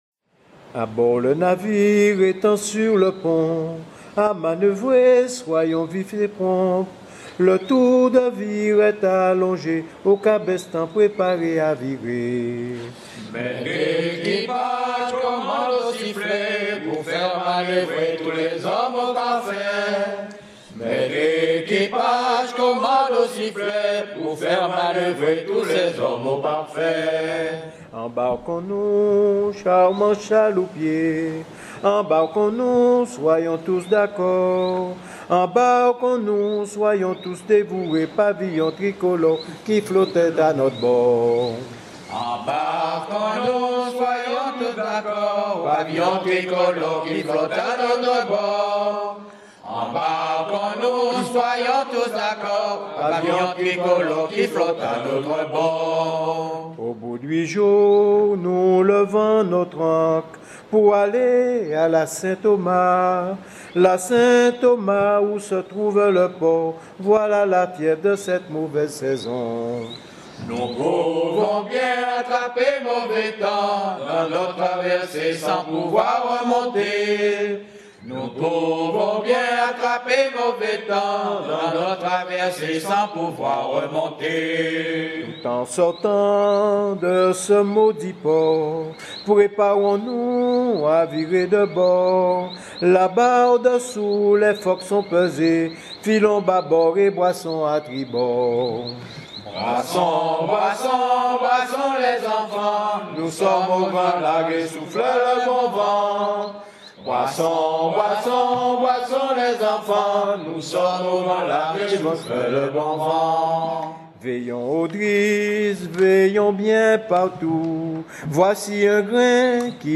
Enregistré sur l'île de la Désirade en 2009
Genre strophique